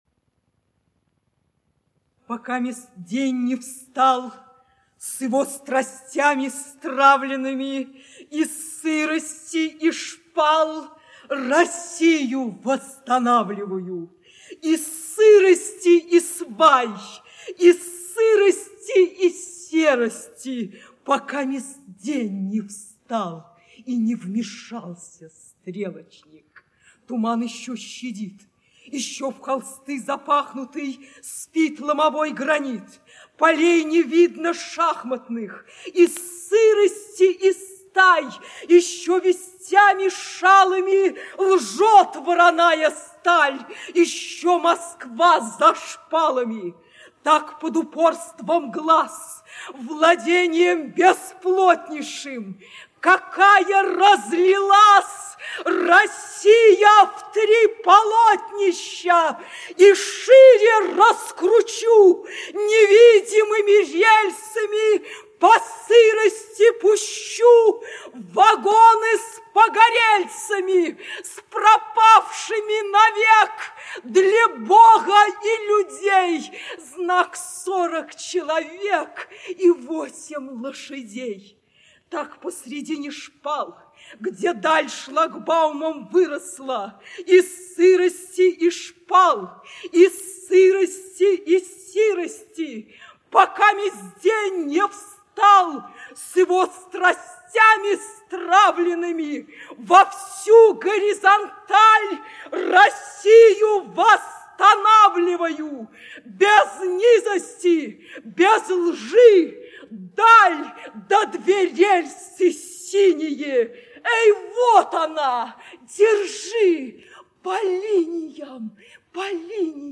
Cvetaeva-Marina-Cvetaeva-Rassvet-na-relsah-chitaet-Tatyana-Doronina-stih-club-ru.mp3